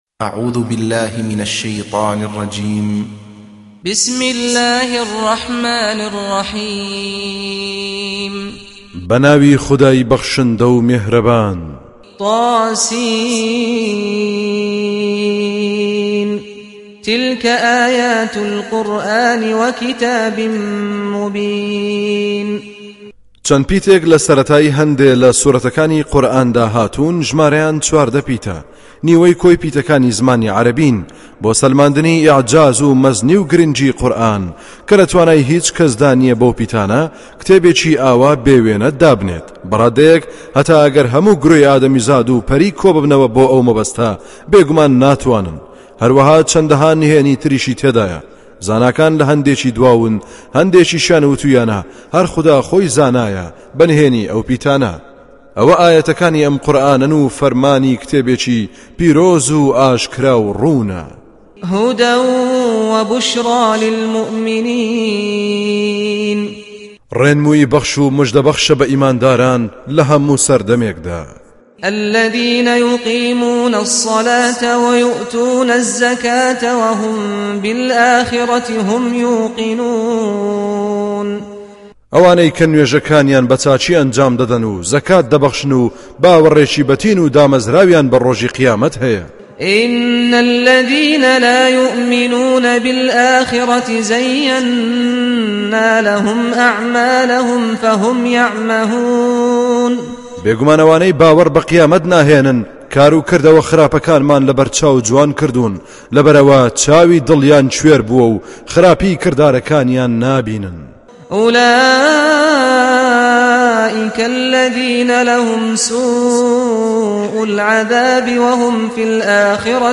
Home Of Kurdish Translation With Qari SaadAl-Ghamdi
Surah Sequence تتابع السورة Download Surah حمّل السورة Reciting Mutarjamah Translation Audio for 27. Surah An-Naml سورة النّمل N.B *Surah Includes Al-Basmalah Reciters Sequents تتابع التلاوات Reciters Repeats تكرار التلاوات